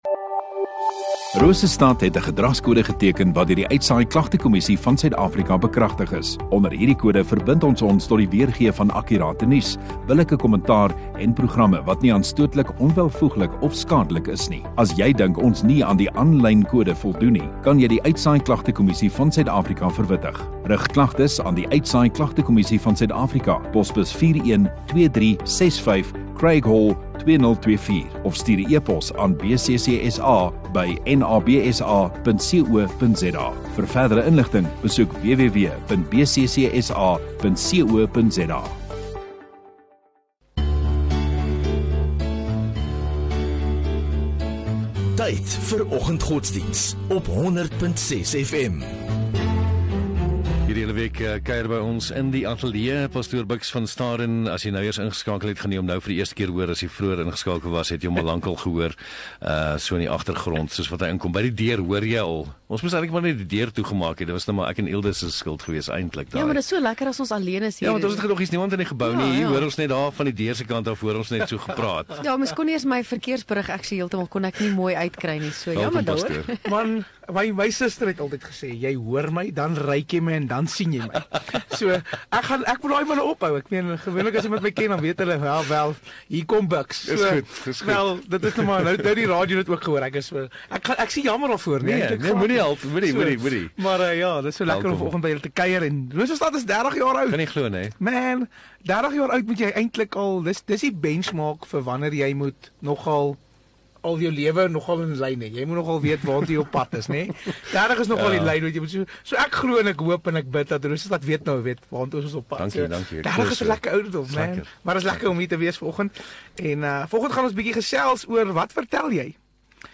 19 Mar Dinsdag Oggenddiens